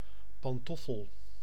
Ääntäminen
Synonyymit chausson cafignons Ääntäminen France: IPA: [pɑ̃.tufl] Haettu sana löytyi näillä lähdekielillä: ranska Käännös Ääninäyte Substantiivit 1. slipper {m} 2. pantoffel {m} 3. slof Suku: m .